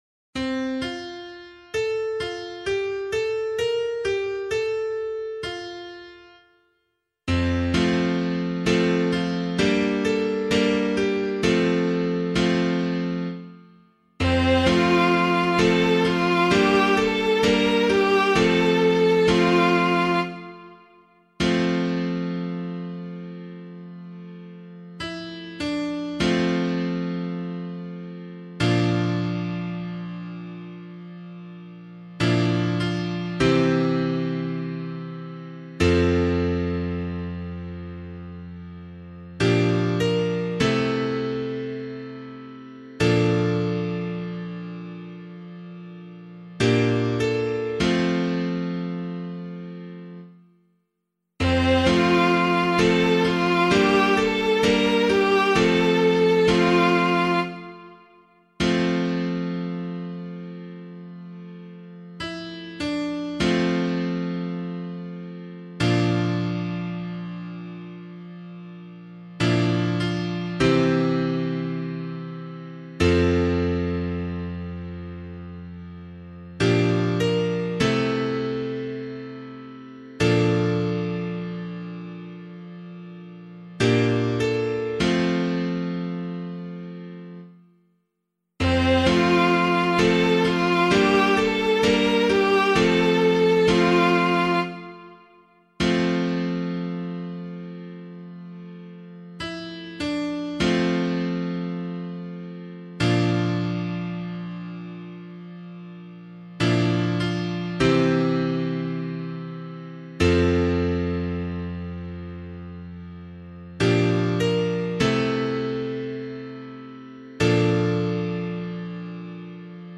041 Ordinary Time 7 Psalm C [APC - LiturgyShare + Meinrad 6] - piano.mp3